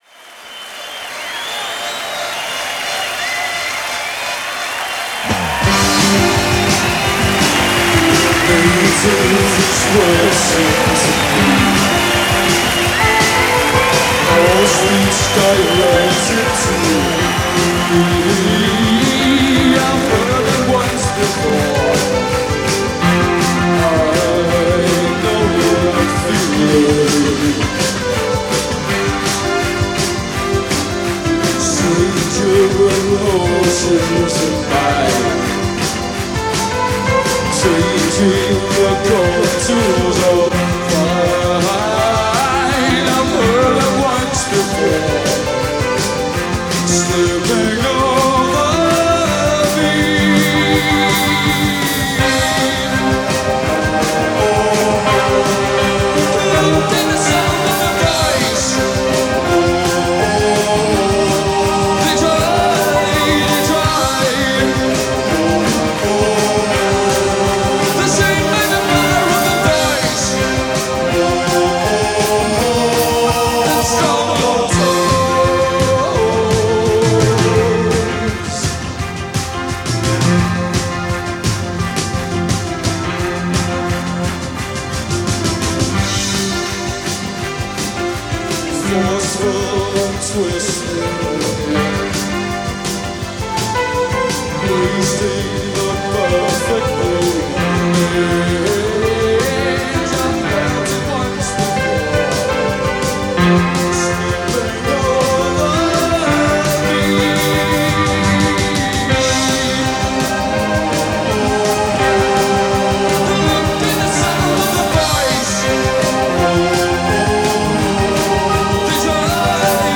were a British new romantic band